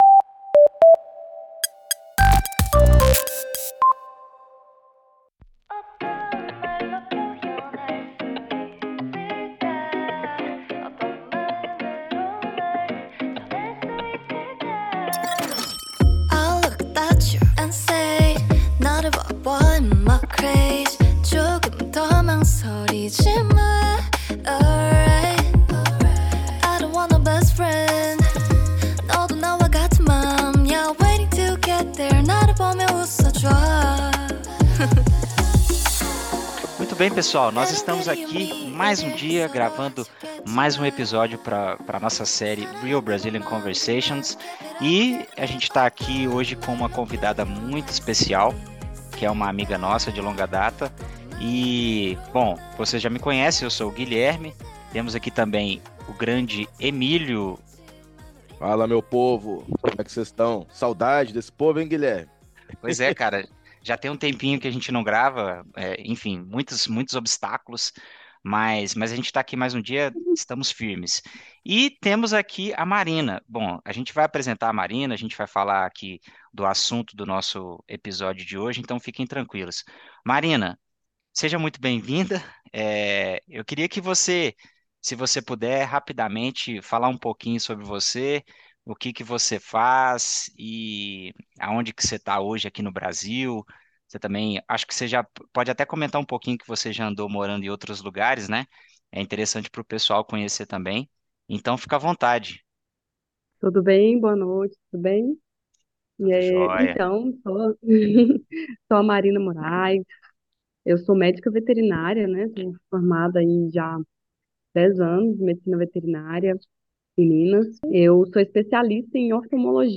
real-brazilian-conversations-special-paws-tails-uncovering-pet-care-tips-and-brazils-favorite-pets.mp3